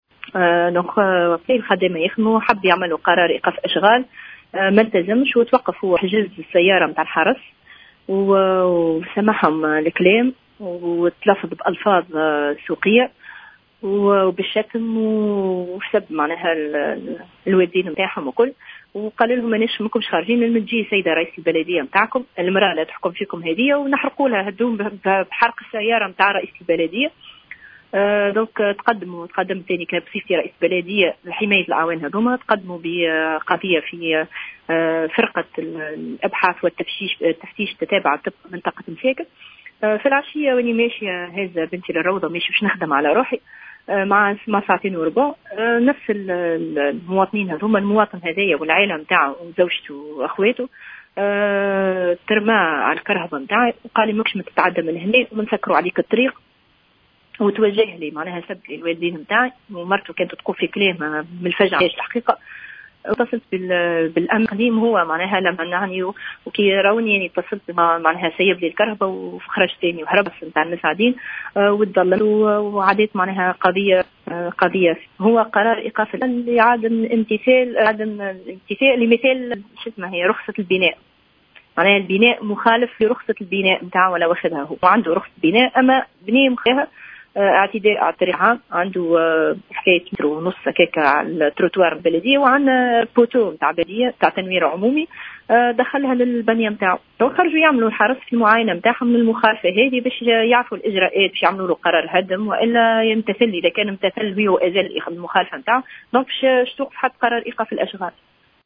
وأكدت الشيخ علي، في تصريح للجوهرة أف أم، اليوم الجمعة، أن هذا المواطن، وعدد من أقاربه، قد عمدوا إلى قطع الطريق أمامها، وتوجهوا لها بألفاظ نابية بسبب قرار وقف أشغال البناء، كما قاموا أيضا بالاعتداء لفظيا على الدورية الأمنية التي كلفت بإبلاغهم بالقرار.